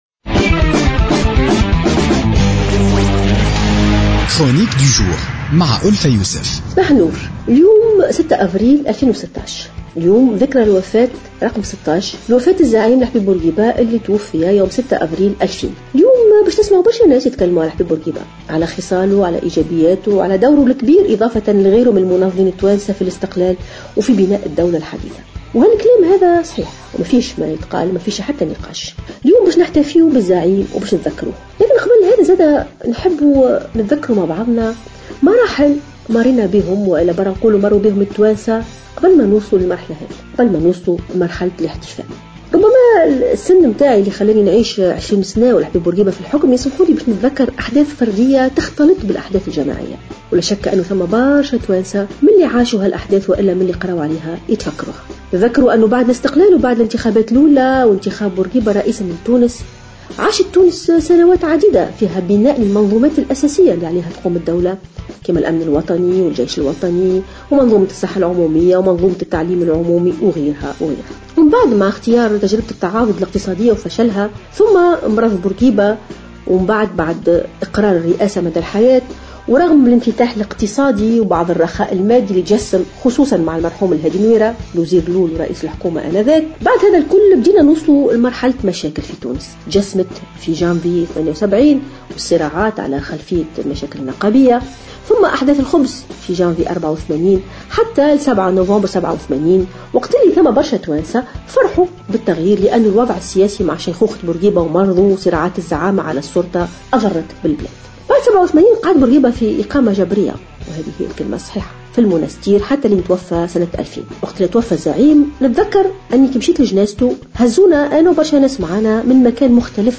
تحدثت الجامعية والمفكرة ألفة يوسف في افتتاحية اليوم، الأربعاء عن مسيرة الزعيم الراحل الحبيب بورقيبة ودوره في بناء الدولة الحديثة.